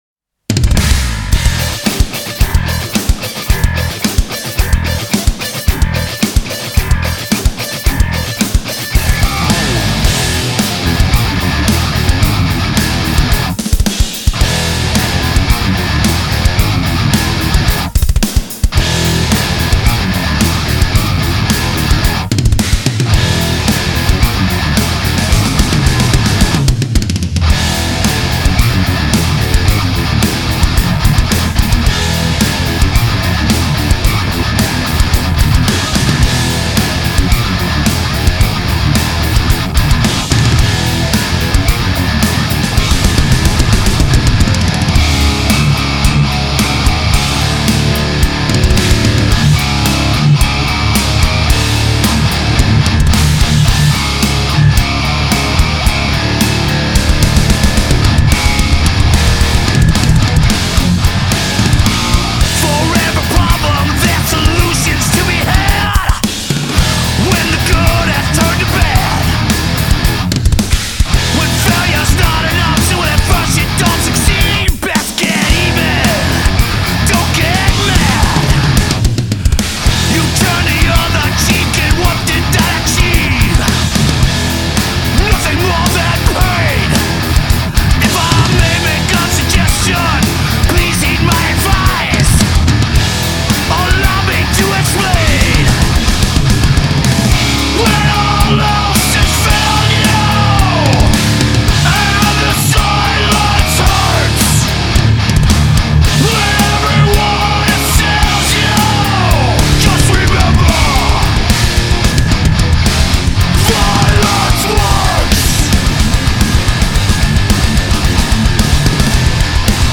Трек размещён в разделе Зарубежная музыка / Метал.